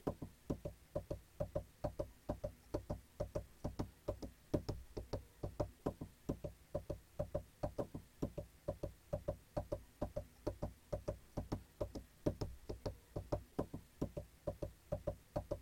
巴蒂达心肌梗塞 心脏跳动
描述：用过滤器、lo fi、失真和其他我记不清楚的东西进行维护。
标签： 科拉桑 心脏搏动 batida-cardiaca 心脏
声道立体声